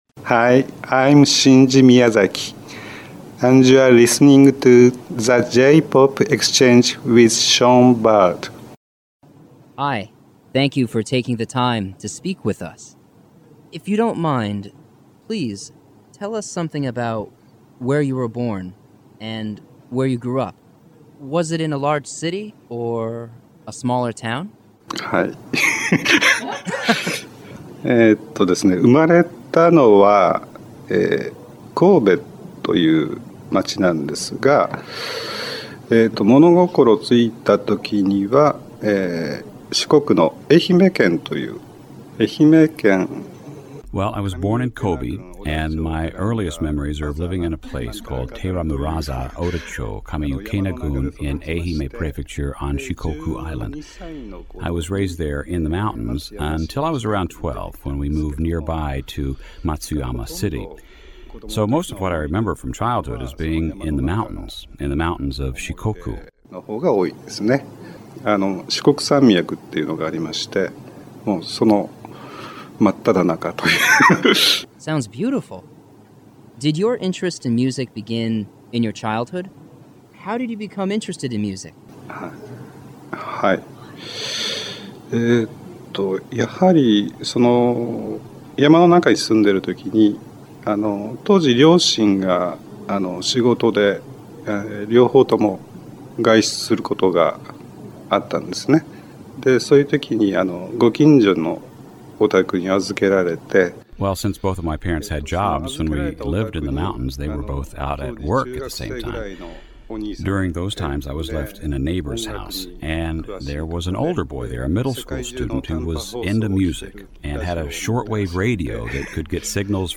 View Transcript of Radio Interview